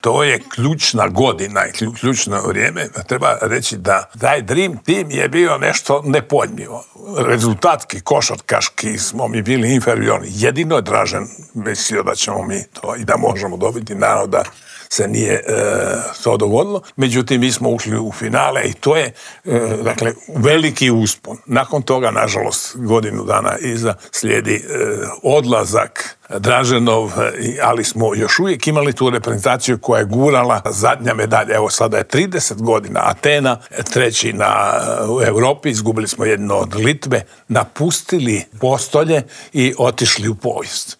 Kako sve krenulo prema dolje, pokušali smo odgonetnuti u Intervjuu tjedna Media servisa u kojem je gostovao bivši TV komentator, legendarni Slavko Cvitković.